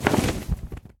Sound / Minecraft / mob / enderdragon / wings5.ogg
wings5.ogg